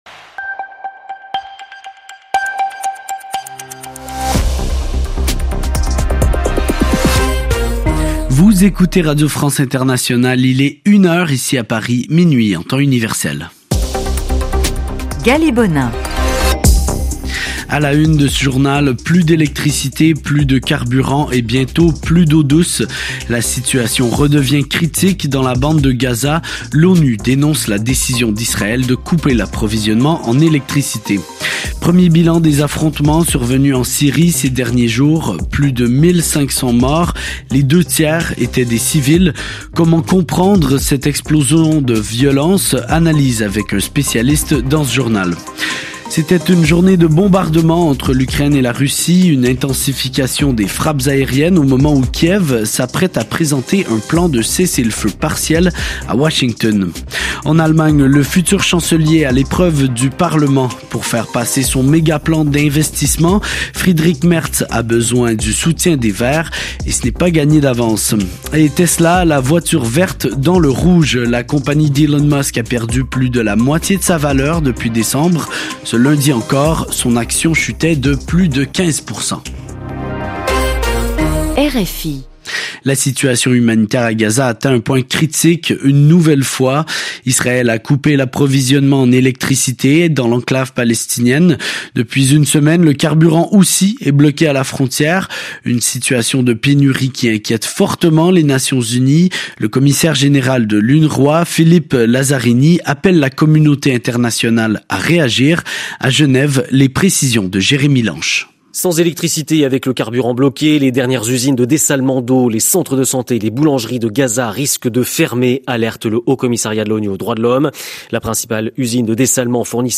Journal 11/03/2025 00h00 GMT